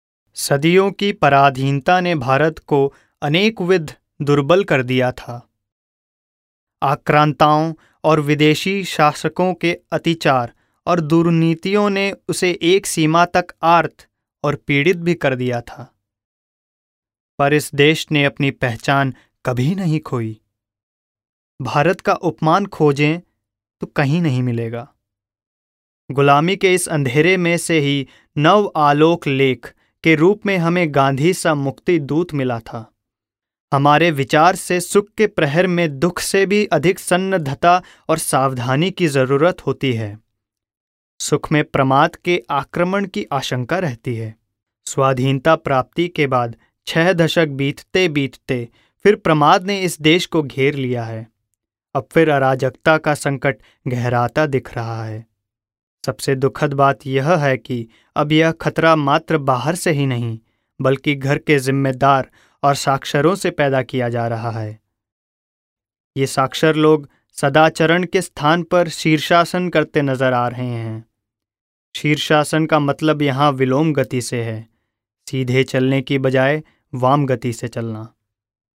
Young, middle aged voice with great command over diction in Hindi, English (Indian) and Marwari (Rajasthani).
Sprechprobe: eLearning (Muttersprache):